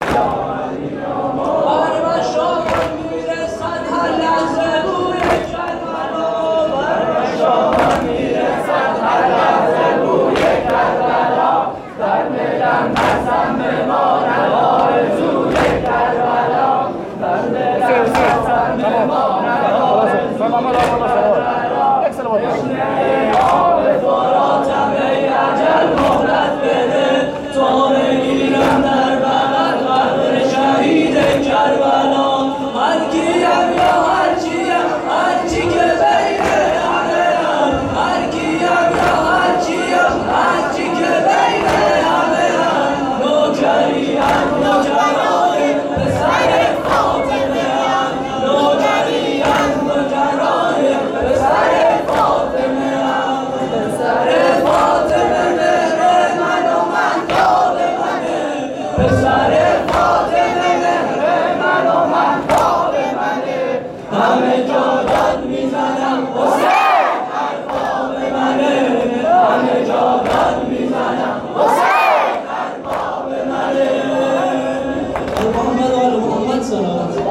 مسجد حضرت ابوالفضل (ع)
شب دوم قدر (21رمضان) 1440